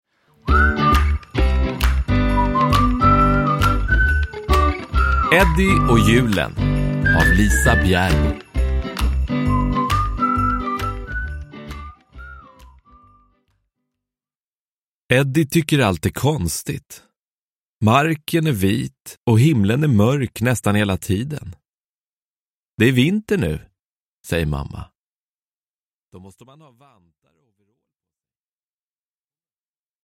Eddie och julen – Ljudbok – Laddas ner